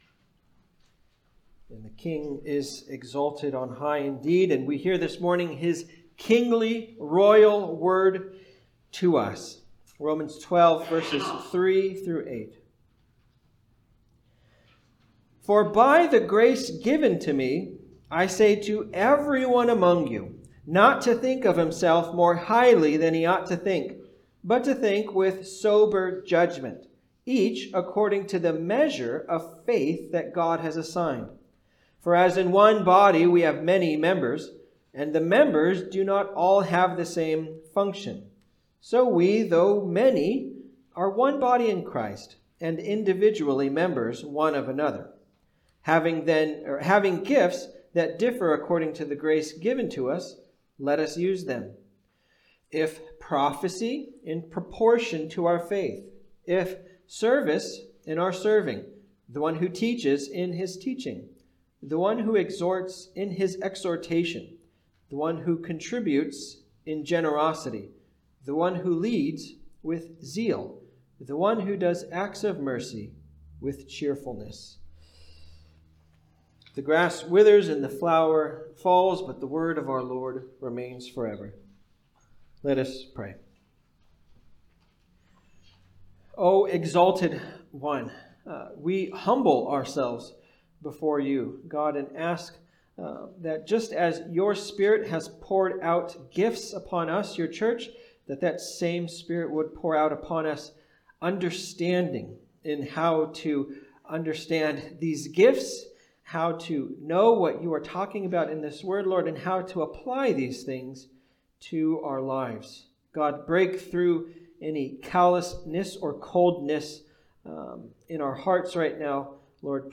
Romans 12:3-8 Service Type: Sunday Service « Not Conformed